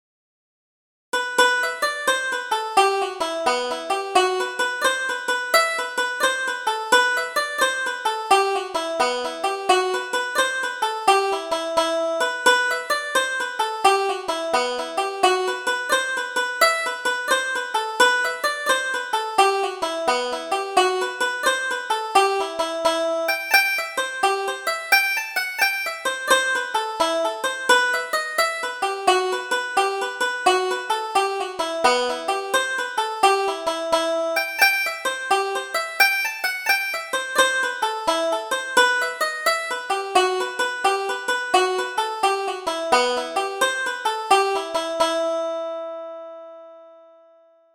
Double Jig: The White Petticoat